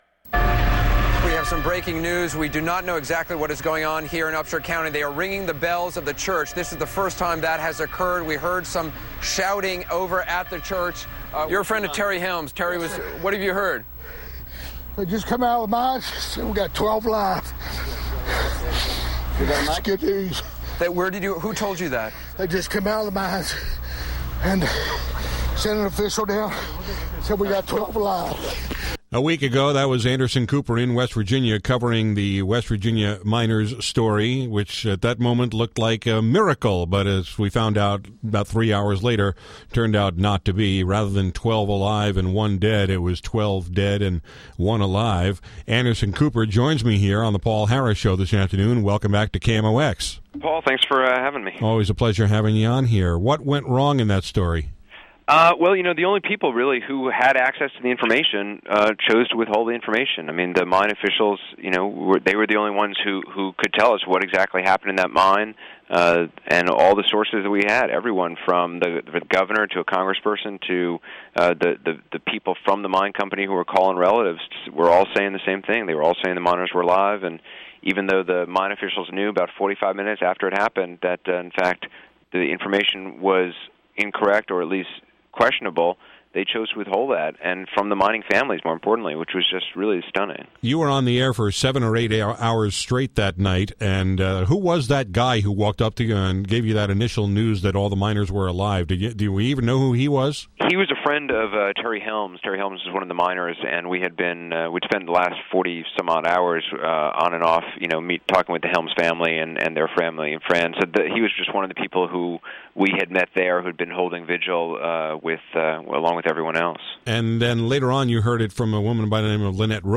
Here’s my conversation with CNN’s Anderson Cooper about what went wrong in covering the West Virginia mine explosion and its aftermath last week, and who is to blame for the incorrect information getting out on his broadcast. We also got into a lengthy discussion of his trip back to Baghdad last month, including how he answers the critics who say Americans aren’t getting a real picture of what’s happening there, how he stayed safe in a nation where yet another journalist has just been kidnapped, and whether the Iraqi police and army are anywhere close to taking responsibility for the security of their own country.